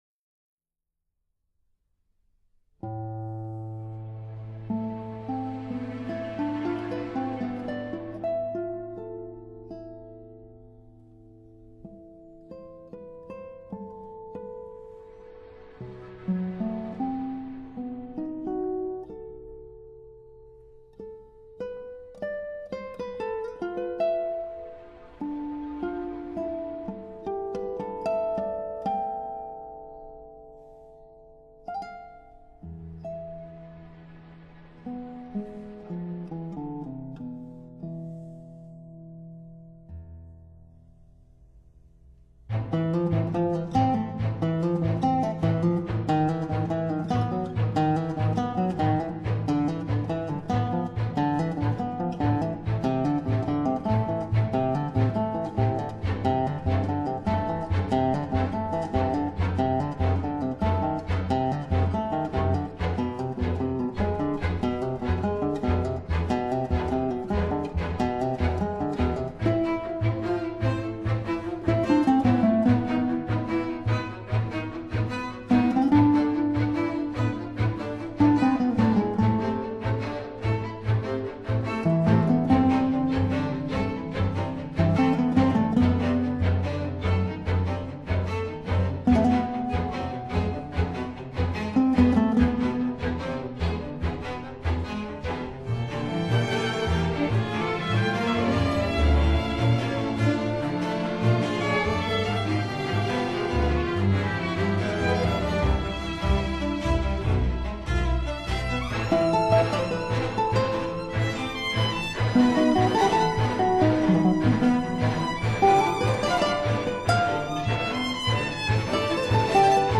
Genre: Classical Guitar